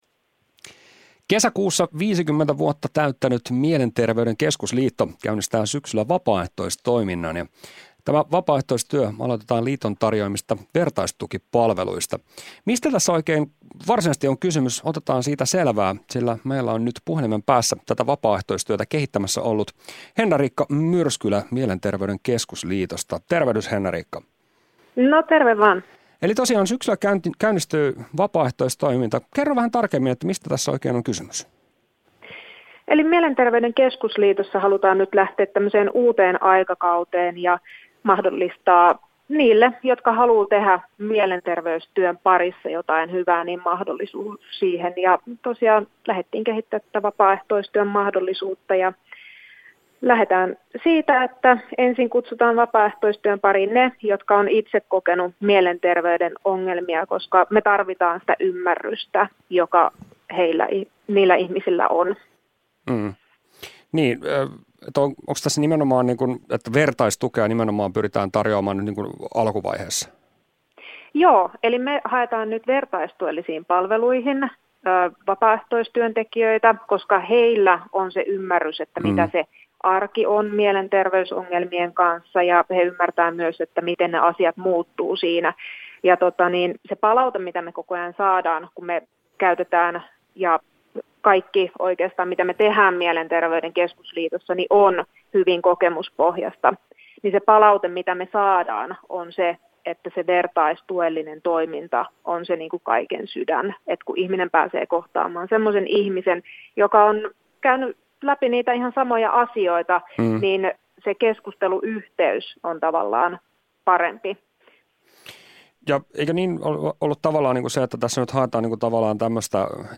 Päivän haastattelu